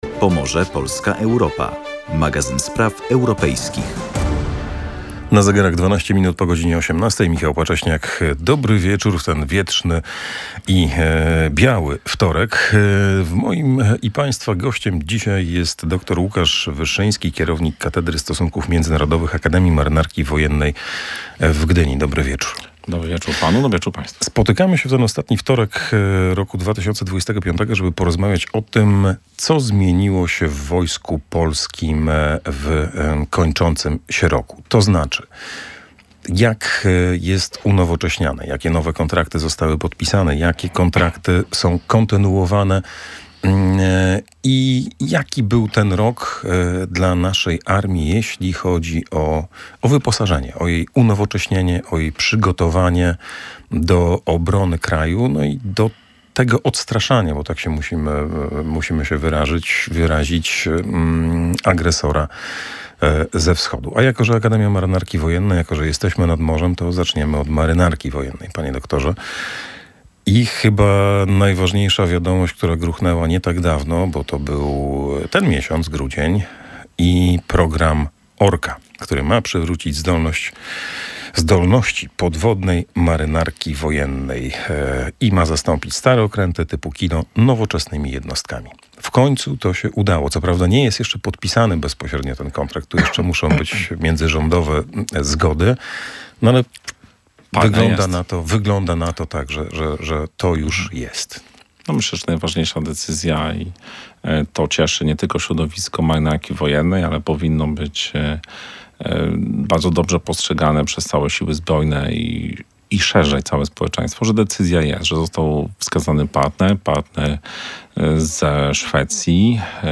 Jakie decyzje podjęto i co one oznaczają dla polskiej armii? O tym rozmawialiśmy w audycji „Pomorze, Polska, Europa”.